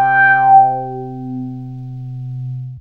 WOBBLER 1.wav